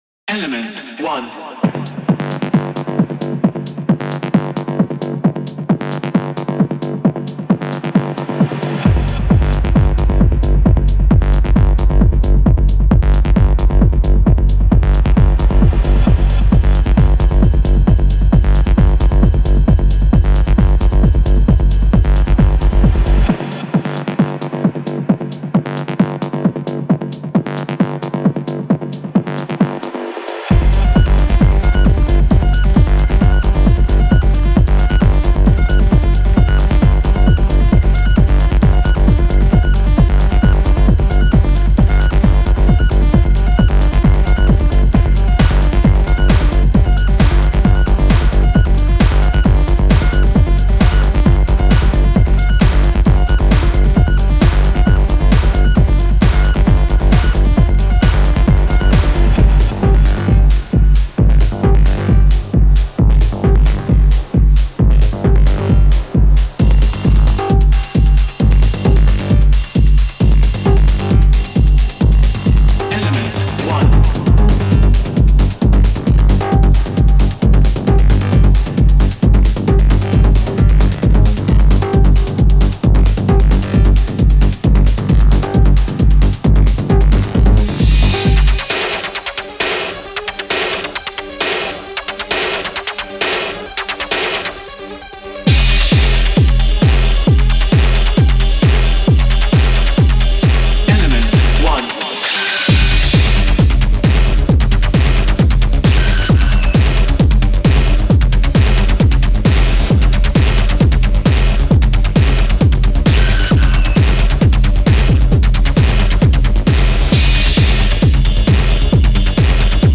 Genre:Industrial Techno
これは、インダストリアルテクノを特徴付ける暗く機械的なエネルギーに深く切り込むものです。
荒々しいアナログトーン、アシッドベースライン、深いサブ、パンチのあるEBMシーケンスが期待できます。
デモサウンドはコチラ↓